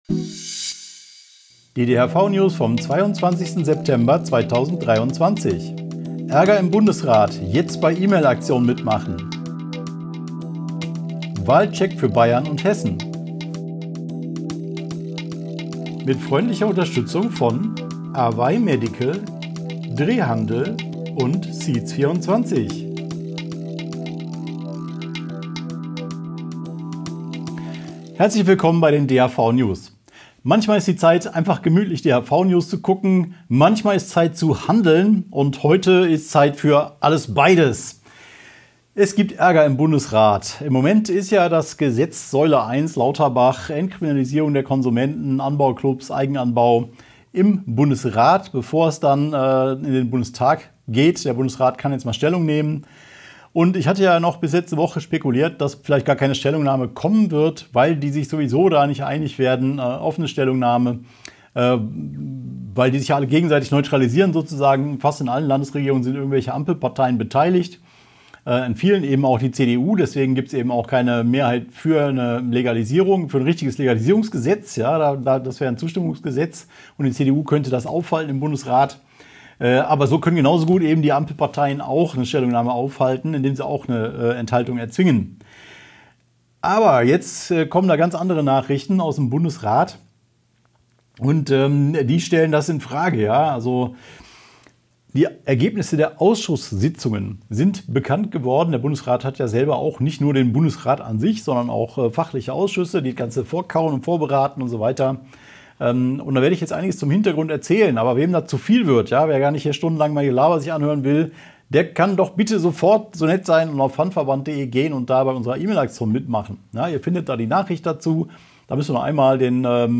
| DHV-Video-News #394 Die Hanfverband-Videonews vom 22.09.2023 Die Tonspur der Sendung steht als Audio-Podcast am Ende dieser Nachricht zum downloaden oder direkt hören zur Verfügung.